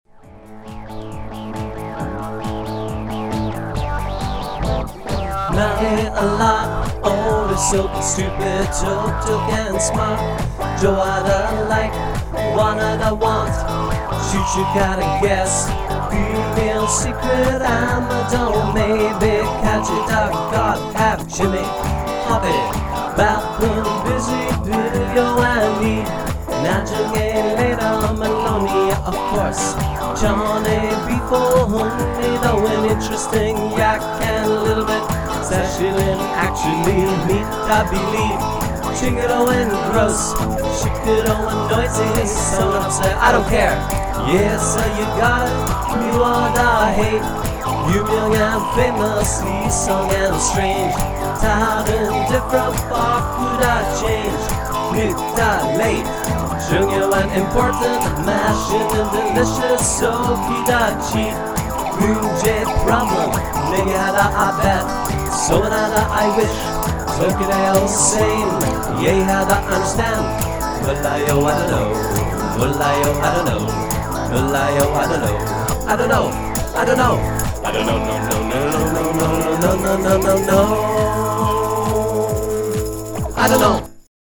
MP3 Song